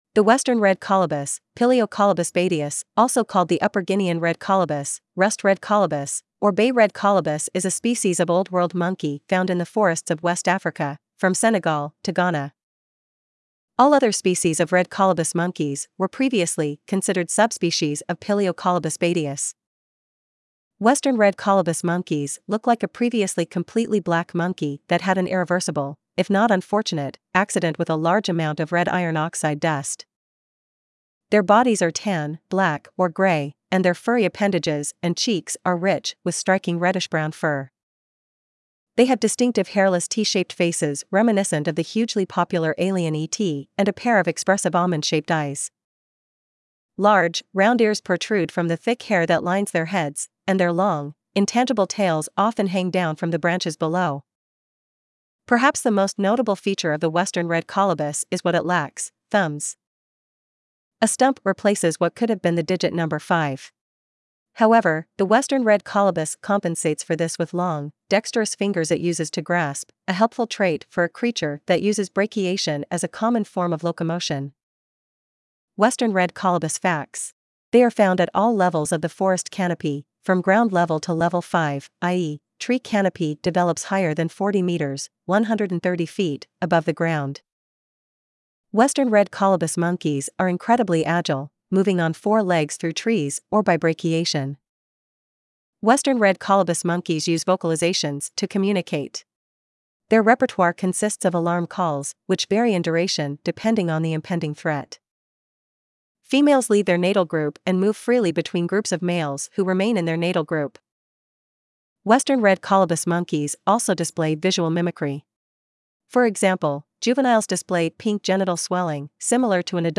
Western Red Colobus
• Western Red Colobus Monkeys use vocalizations to communicate. Their repertoire consists of alarm calls, which vary in duration depending on the impending threat.
western-red-colobus.mp3